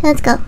Worms speechbanks
Fire.wav